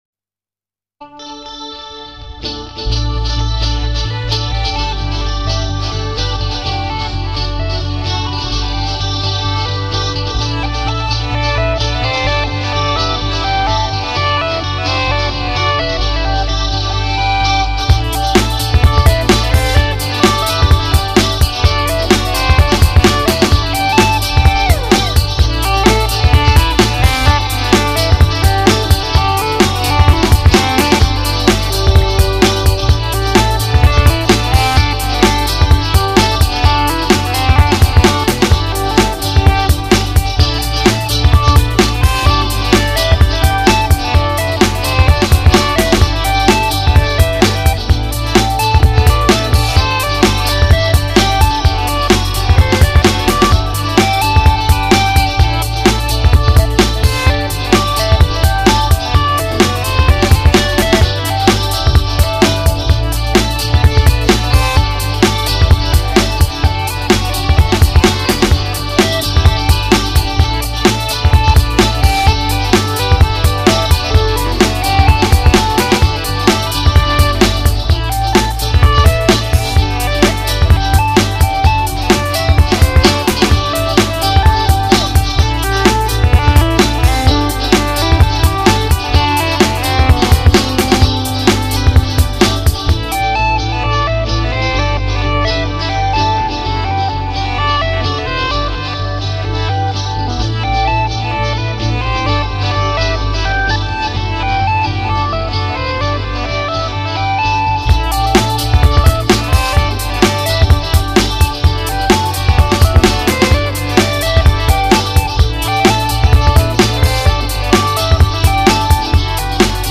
Five instrumental tracks